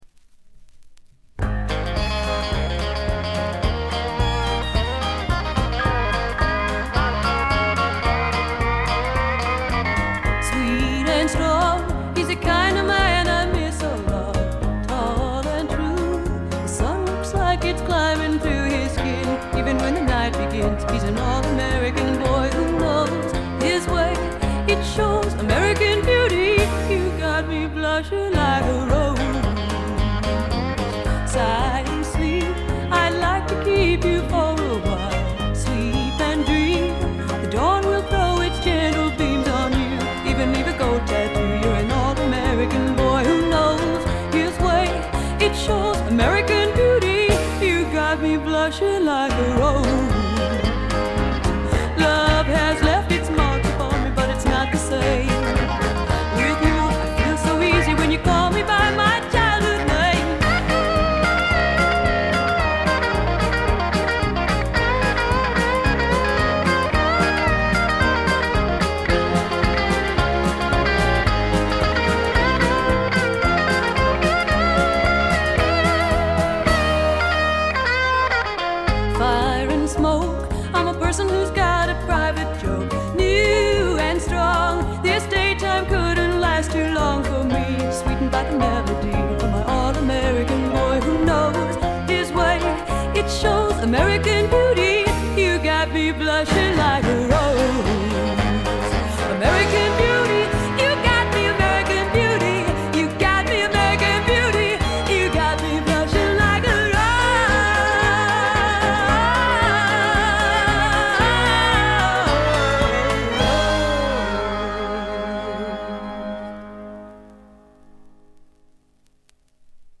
部分試聴ですがわずかなノイズ感のみ。
内容はファースト同様のいかにもなピアノ系女性シンガー・ソングライター路線でさほど南部色はありません。
試聴曲は現品からの取り込み音源です。
Recorded at Muscle Shoals Sound Studios, July, 1975.